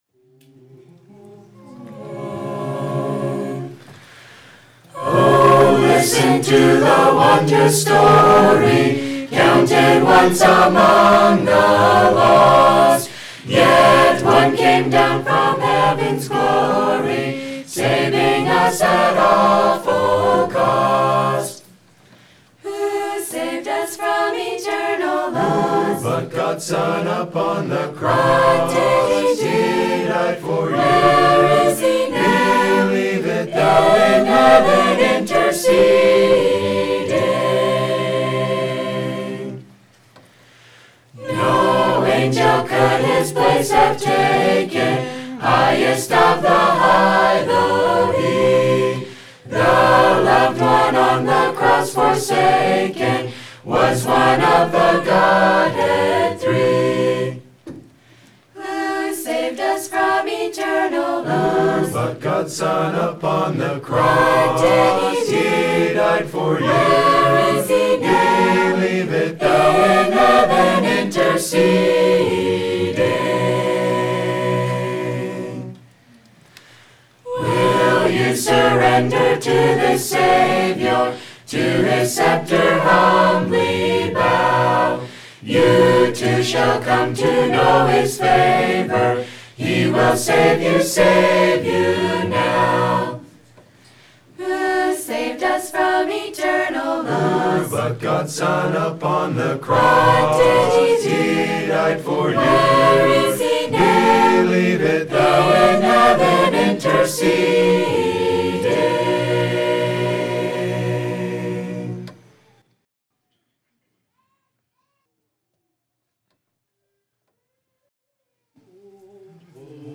2019 HMB Christmas Program
Speaker: HMB Chorus